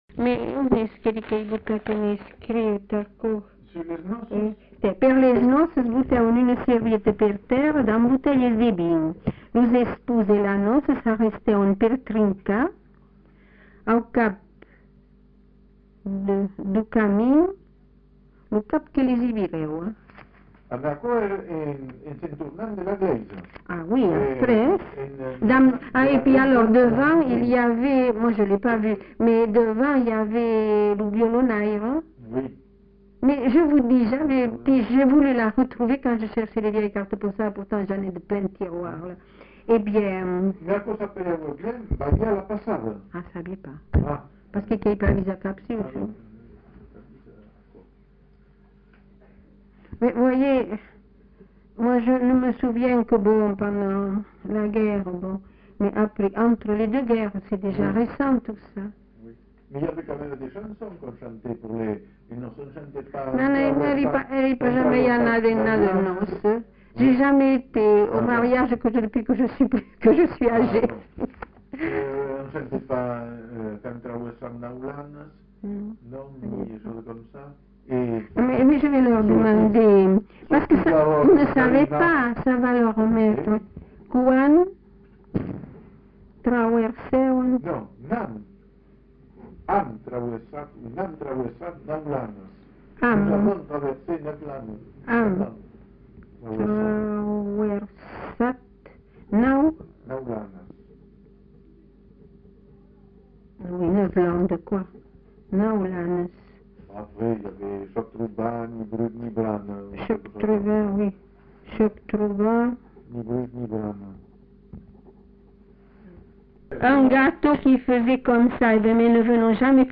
Lieu : Captieux
Genre : témoignage thématique